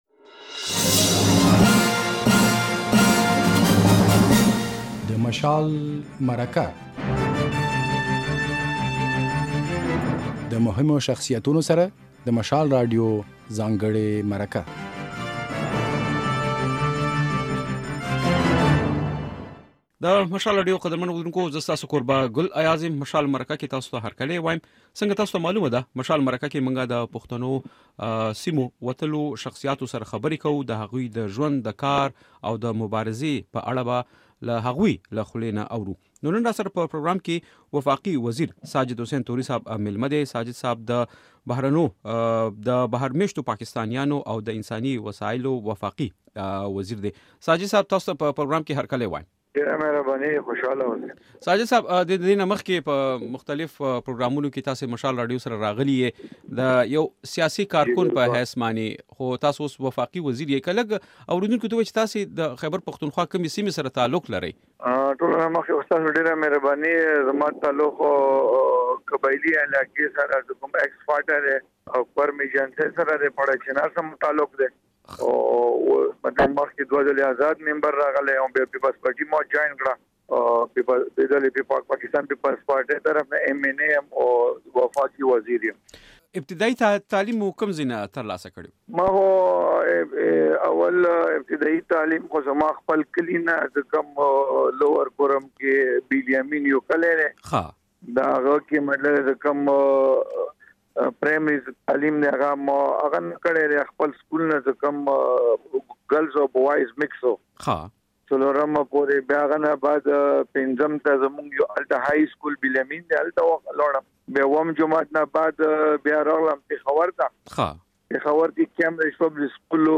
د مشال راډيو په اوونيزه خپرونه "د مشال مرکه" کې دا ځل د بهر ميشتو پاکستانيانو د چارو وفاقي وزير ساجد حسېن توری مېلمه دی. نوموړی وايي، وفاقي حکومت پرېکړه کړې چې هغو ۲۷،۰۰۰ بهرمېشته پاکستانیانو ته پاسپورپوټه نوي کوي چې د بېلا بېلو لاملونو پر بنسټ له اوږدې مودې راهیسې ځنډېدلي ول.